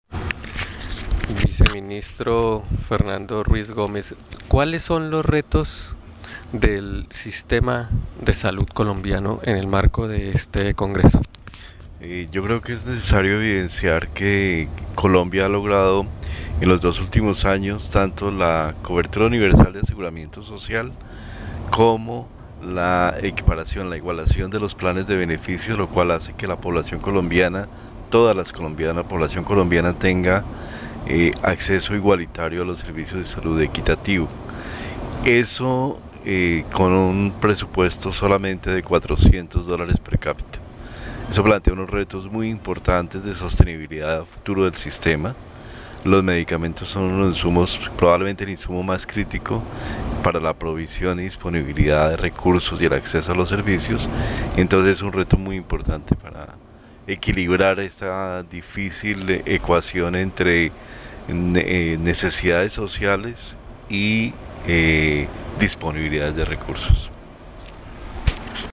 Audio, Viceministro Ruiz habla de los retos de medicamentos en el sistema de salud
Cartagena, 9 de mayo de 2013.- El Viceministro de Salud Pública y Prestación de Servicios,  Fernando Ruiz Gómez, anunció en el Congreso Latinoamericano de la Asociación de Industrias farmacéuticas de Colombia (ASINFAR) que el Gobierno Nacional le apuesta a un mejor desarrollo del sistema en materia de innovación,  propiedad intelectual, sostenibilidad, competencia y acuerdos de Tratados de Libre Comercio y su relación con el sector farmacéutico.